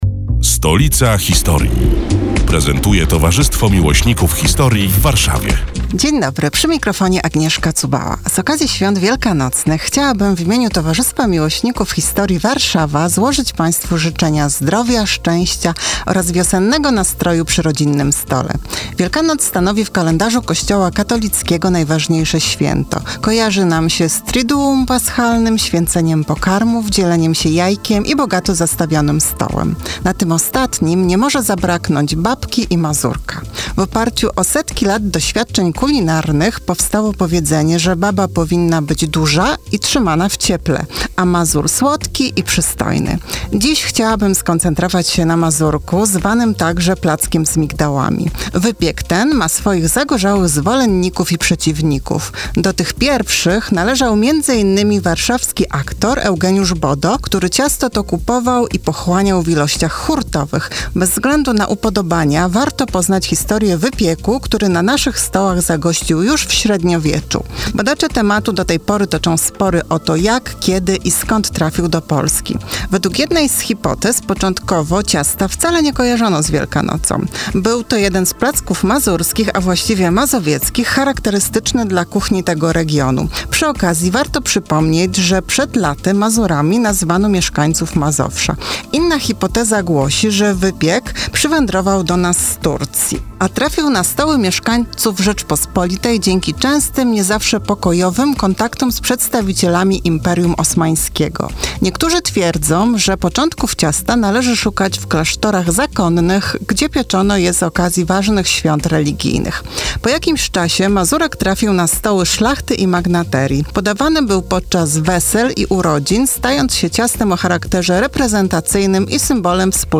112. felieton pod wspólną nazwą: Stolica historii. Przedstawiają członkowie Towarzystwa Miłośników Historii w Warszawie, które są już od trzech lat emitowane w każdą sobotę, w nieco skróconej wersji, w Radiu Kolor.